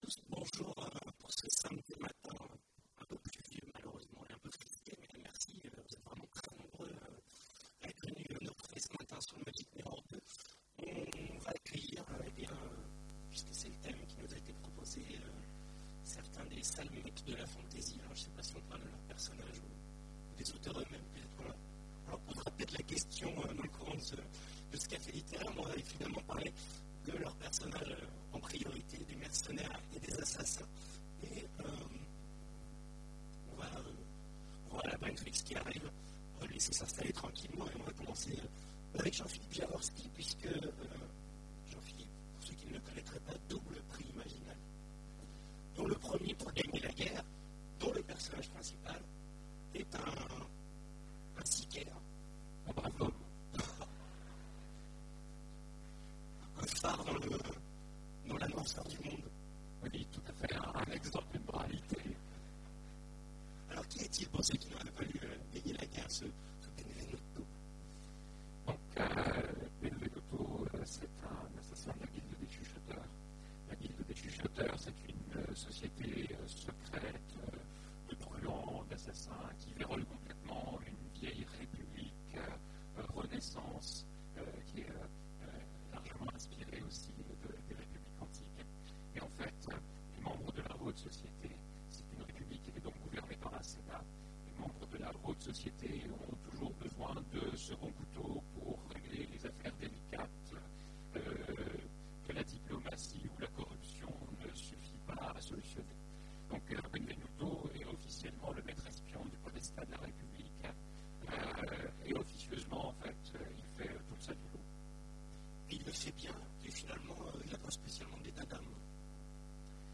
Imaginales 2015 : Conférence Mercenaires et tueurs professionnels